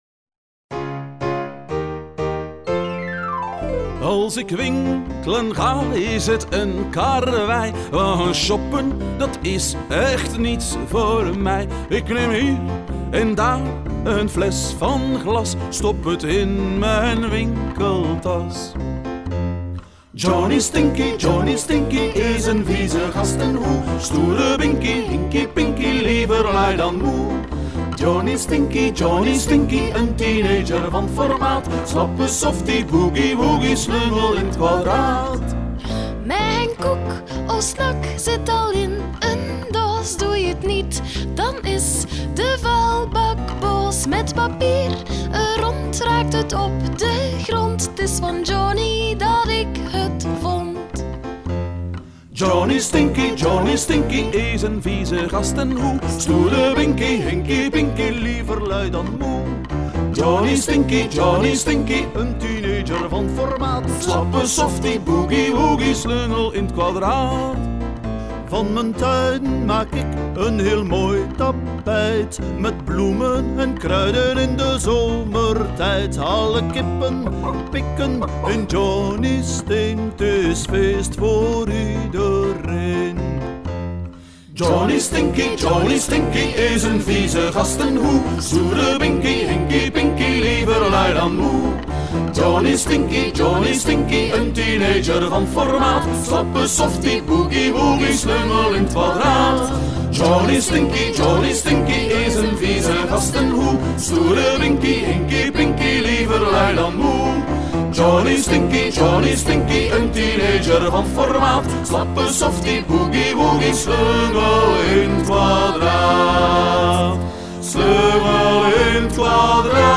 Zingen jullie mee met dit vrolijke lied over afval en hoe het te vermijden?